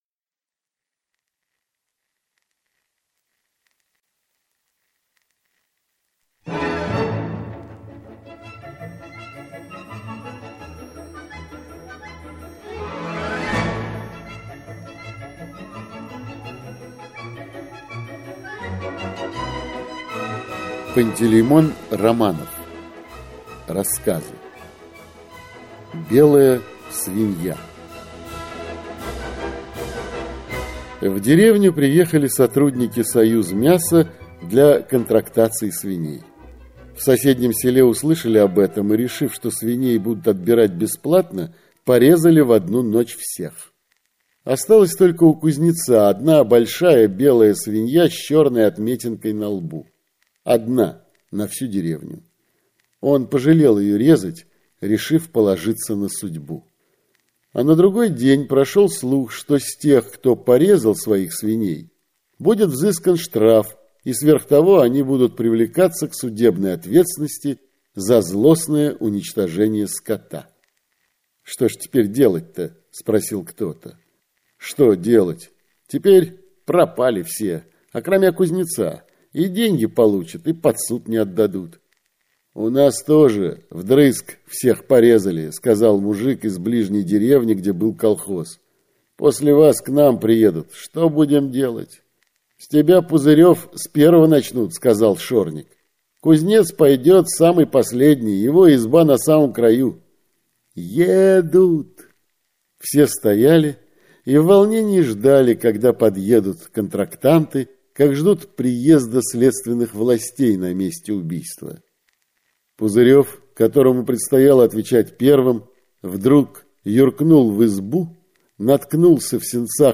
Аудиокнига Вредный человек (сборник) | Библиотека аудиокниг